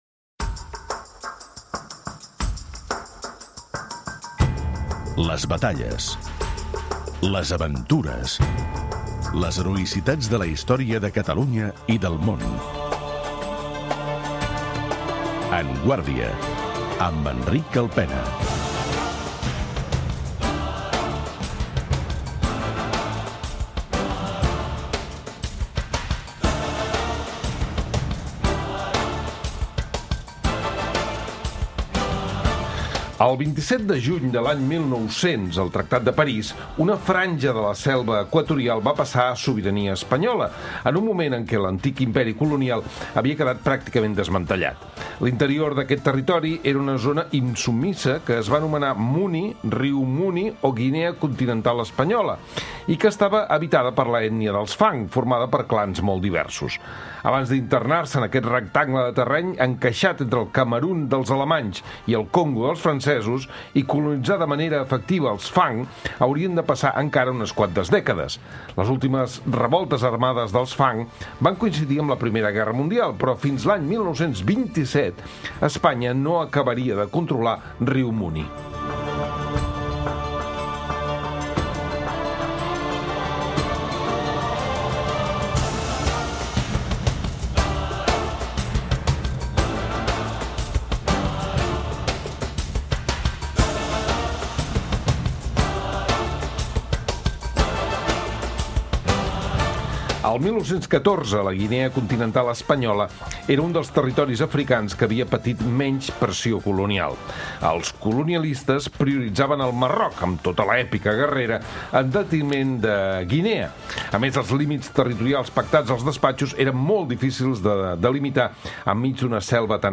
Les últimes revoltes armades dels fang van coincidir amb la Primera Guerra Mundial, però fins a l'any 1927 Espanya no acabaria de controlar Río Muni. En parlem amb l'historiador